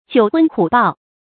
發音讀音
成語拼音 jiǔ hūn hǔ bào